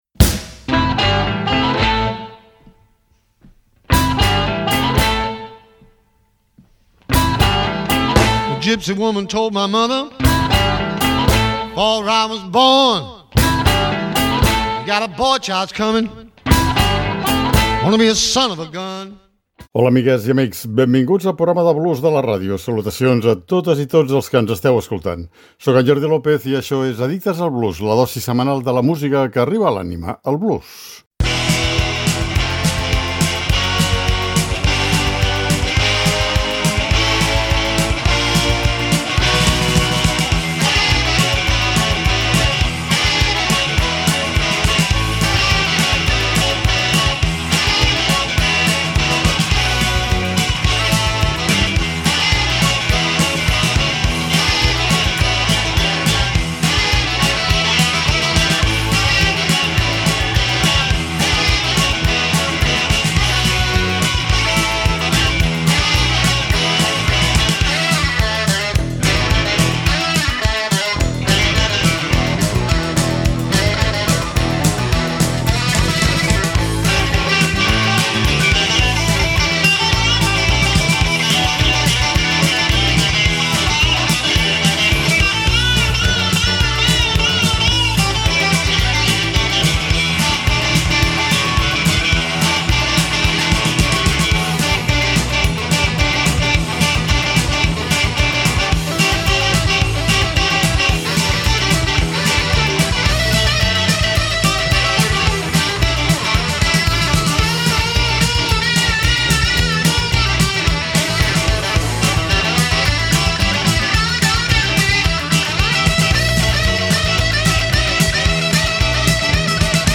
Avui repassarem alguns blues instrumentals comprovant d’aquesta manera que aquest estil de blues és una forma d’art sense límits que mai deixa de sorprendre’ns.